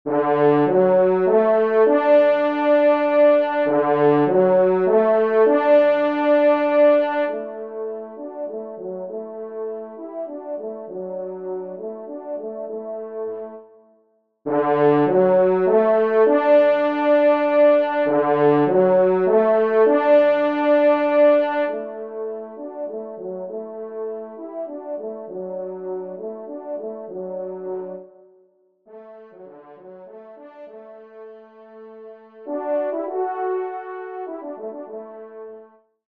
Genre : Divertissement pour Trompes ou Cors
Pupitre 2° Cor